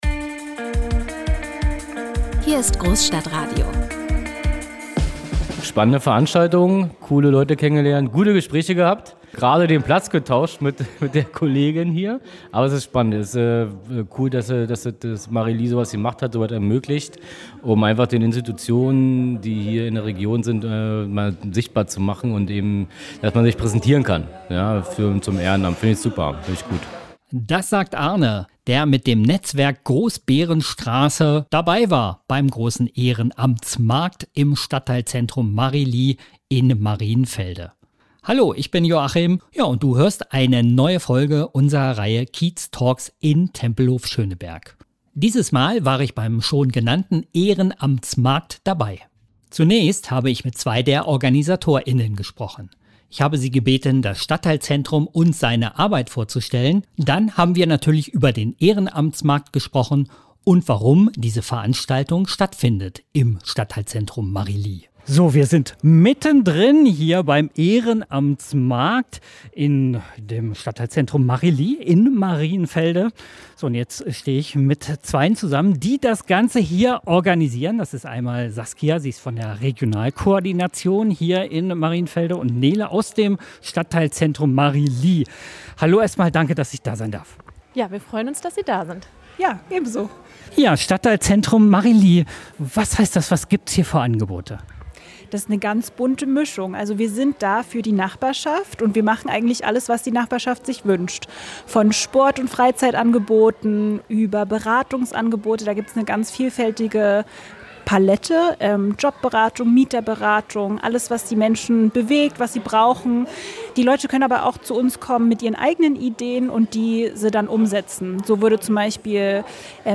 Großstadtradio berichtet in diesem Podcast über den erstmals ausgetragenen Ehrenamtsmarkt in Berlin-Marienfelde.